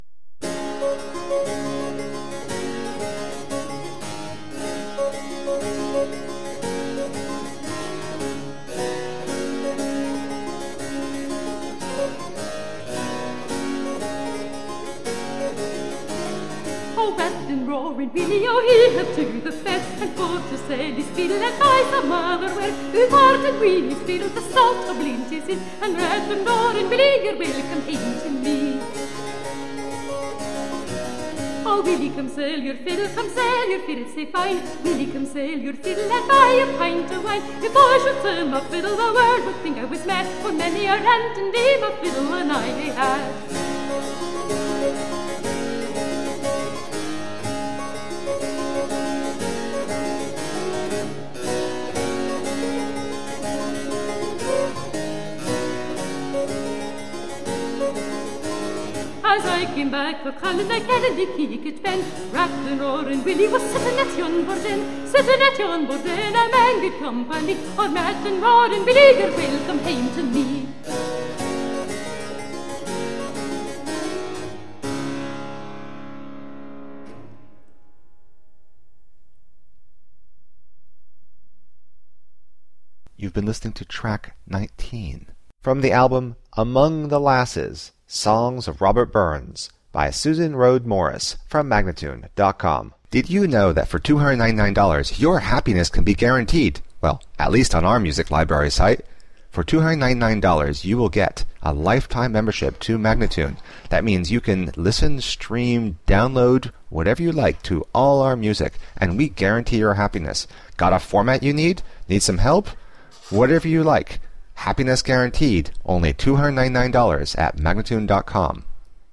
Tagged as: Classical, Folk, Celtic